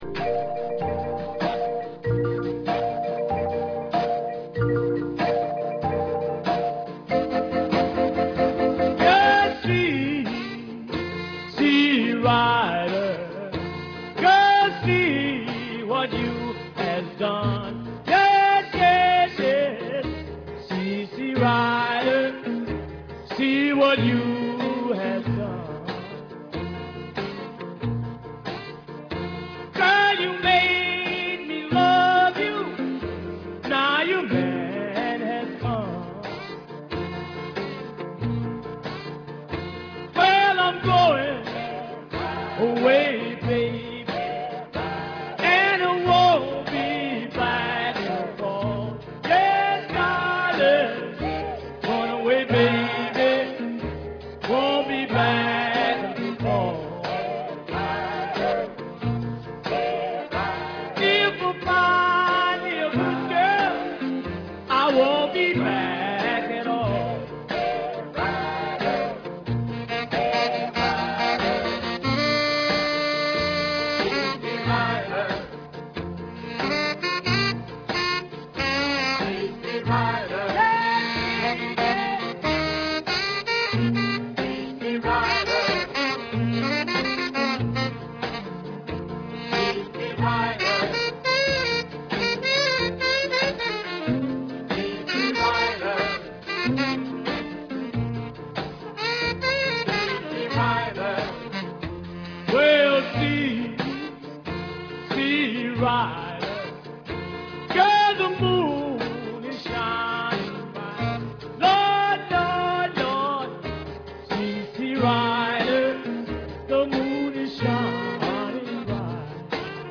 Oldies